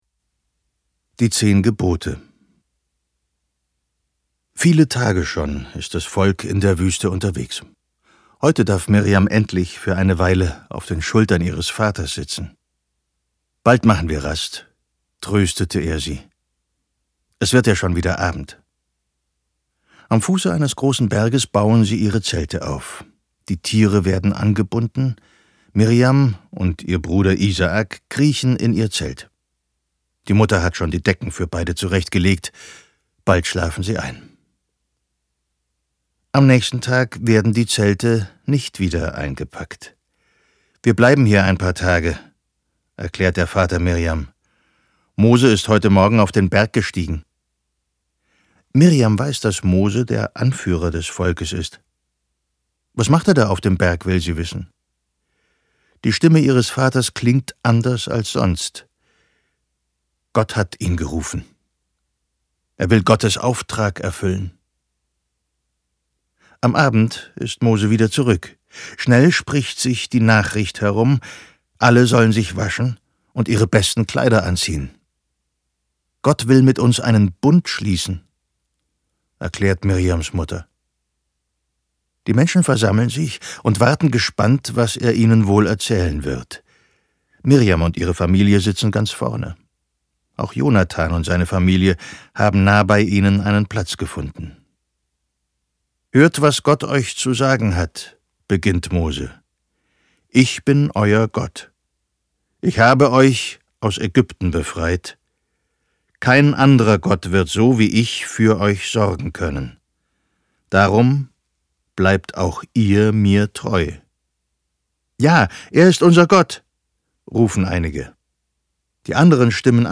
Die erfolgreiche, neue Hörbuchreihe mit Geschichten aus der Bibel für Kinder.